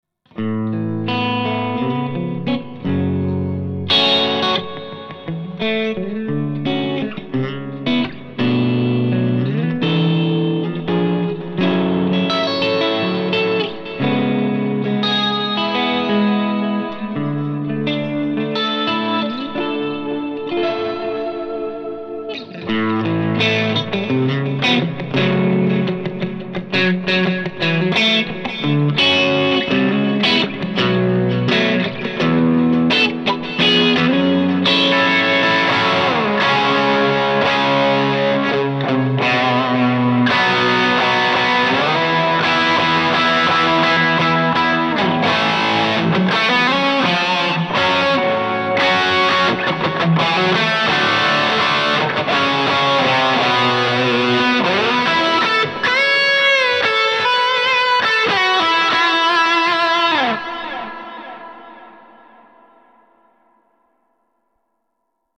This Amp Clone rig pack is made from an Tone King Imperial MKII preamp.
RAW AUDIO CLIPS ONLY, NO POST-PROCESSING EFFECTS